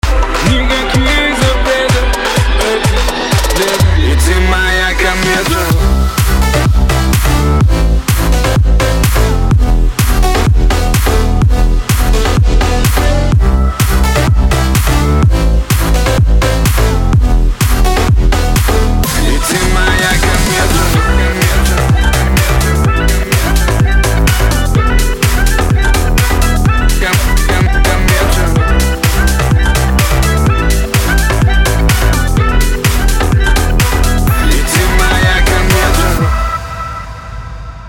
• Качество: 160, Stereo
громкие
future house
энергичные
Заводная хаус-музыка